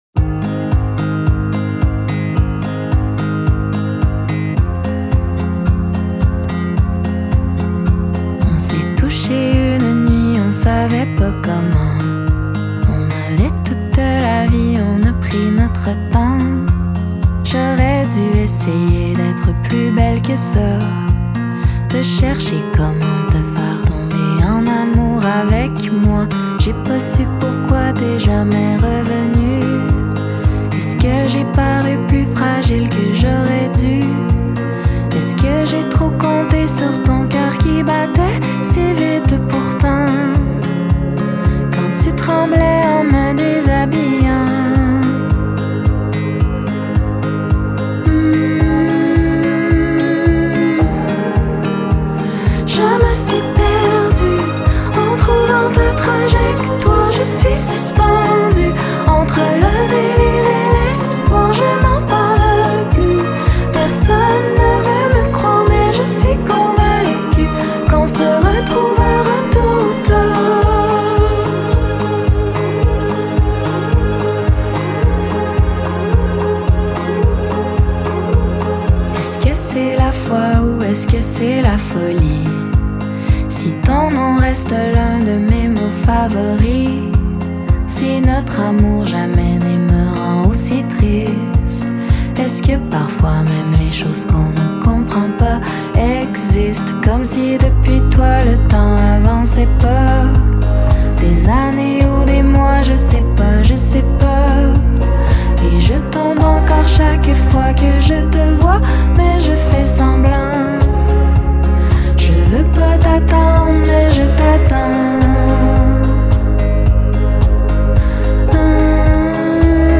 alternant entre perles alt-pop et ballades prenantes.